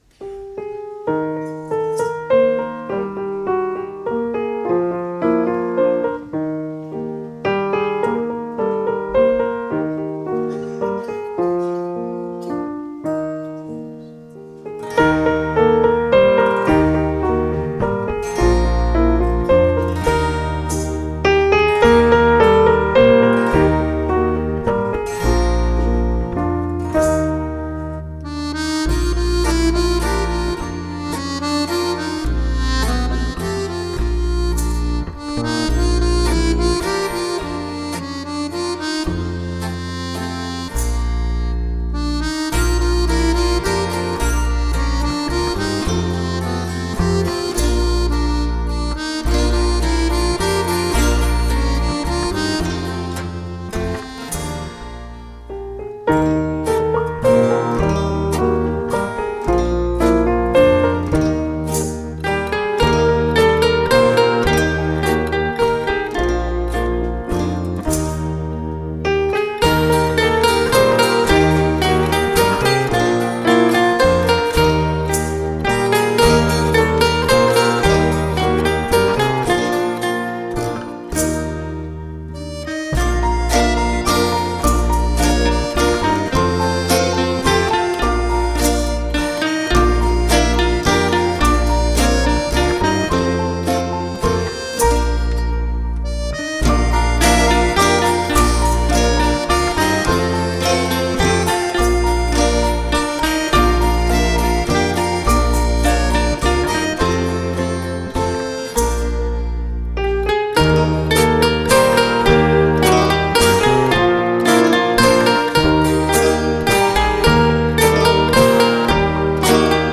Dansa popular que es ballava en festes tradicionals, de ritme pausat i senyorial, semblant al so de la caixa de música. Es diu que Alentorn fou un dels pobles on més temps va perdurar i fou ballada la contradansa, amb tot el seu tipisme i caràcter arcaic de ballet popular.
contradansa.mp3